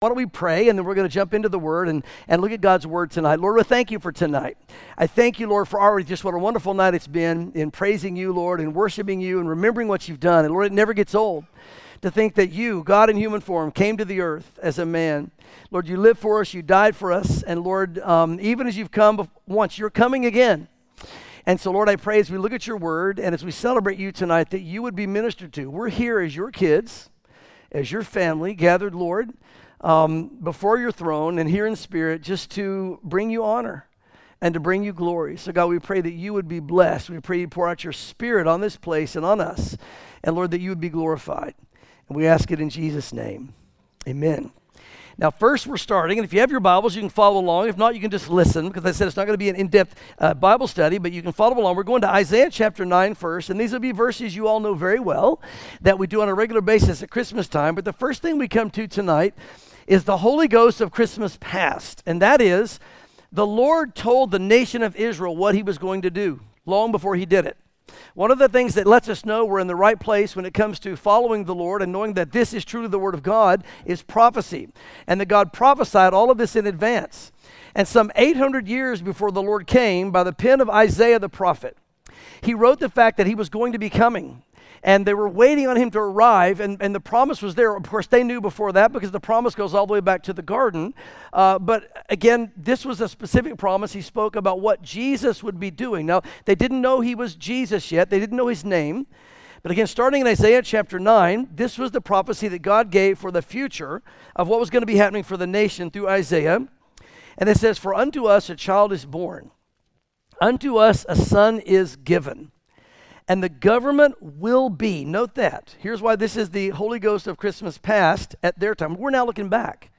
sermons The Holy Ghost of Christmas - Past, Present and Future